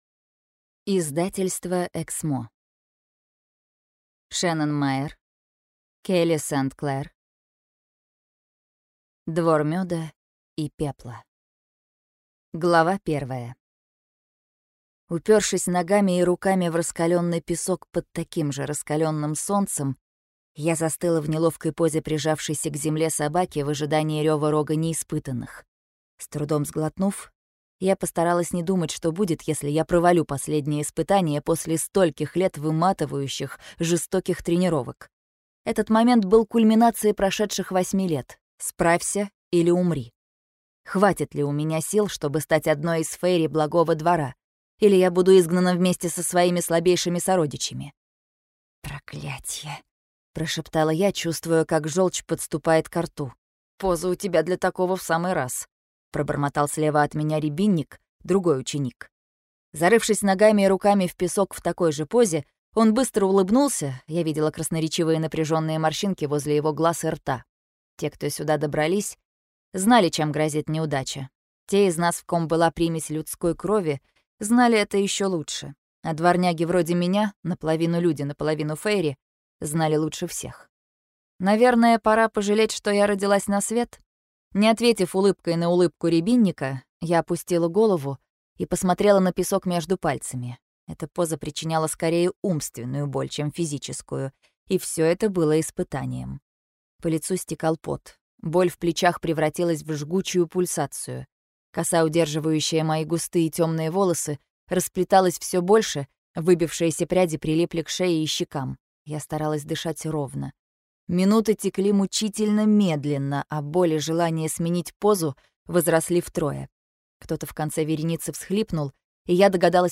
Аудиокнига Двор мёда и пепла | Библиотека аудиокниг